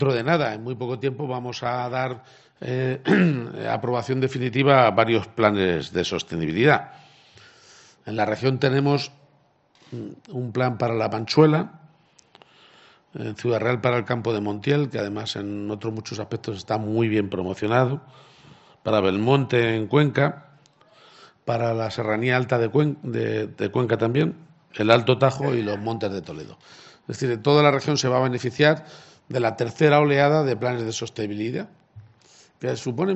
Es decir, toda la región se va a beneficiar de la tercera oleada de planes de sostenibilidad", ha dicho hoy en Consuegra el presidente de Castilla-La Mancha. garciapage_consuegra_planes_de_sostenibilidad.mp3 Descargar: Descargar
garciapage_consuegra_planes_de_sostenibilidad.mp3